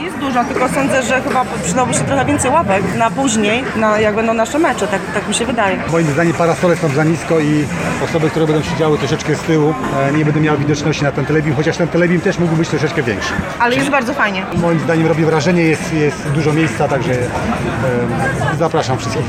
Transmisji inauguracyjnego meczu towarzyszyły konkursy i quiz dla kibiców oraz rozmaite atrakcje dla najmłodszych.